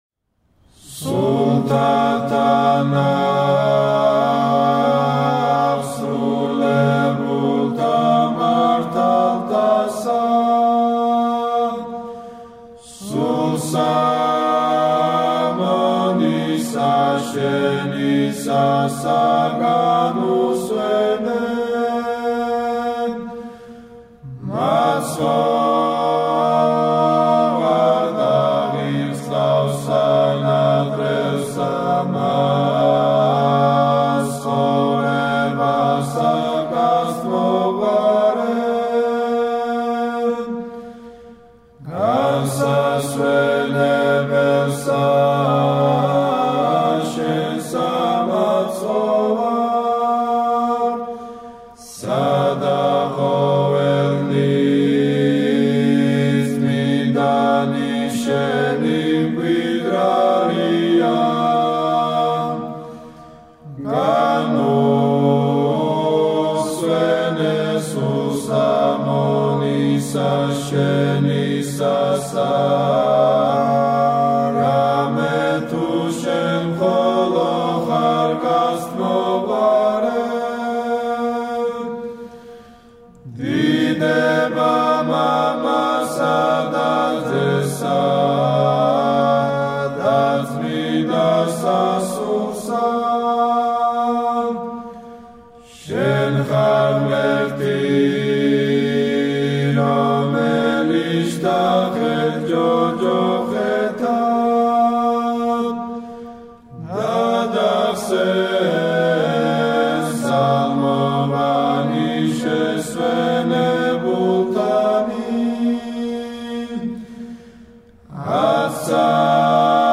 საგალობელი
გუნდი: ანჩისხატი